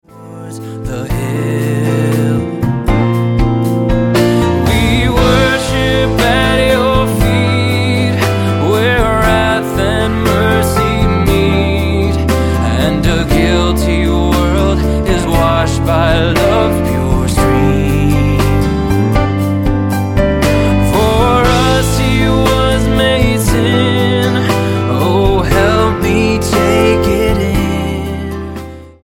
Style: MOR/Soft Pop